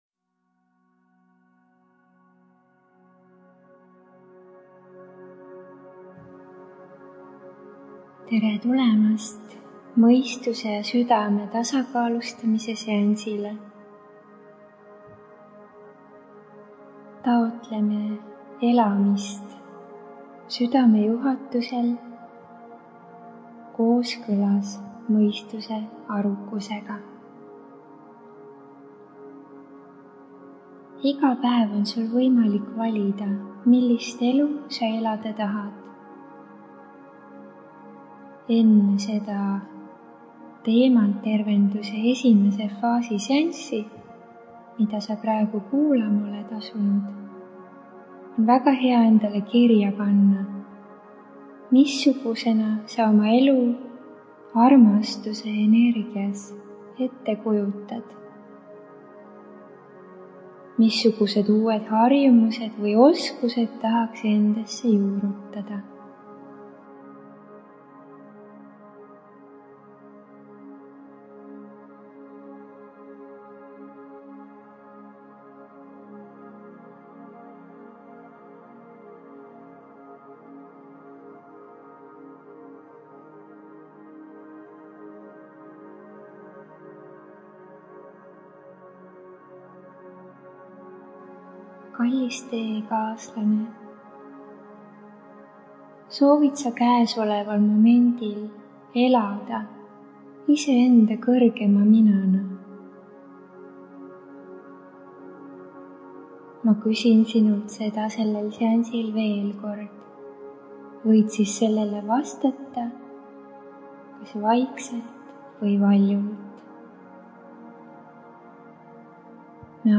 MEDITATSIOON